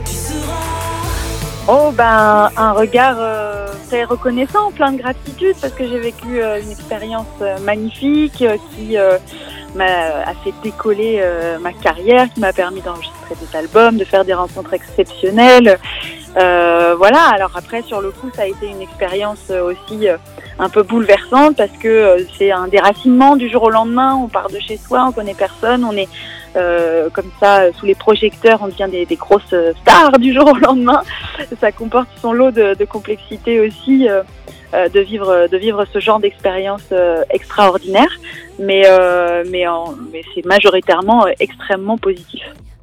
Interview complète à découvrir sur NRJ ce Vendredi matin à 11H40